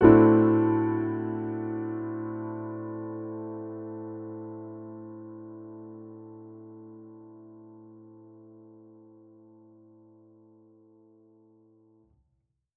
Index of /musicradar/jazz-keys-samples/Chord Hits/Acoustic Piano 1
JK_AcPiano1_Chord-Am13.wav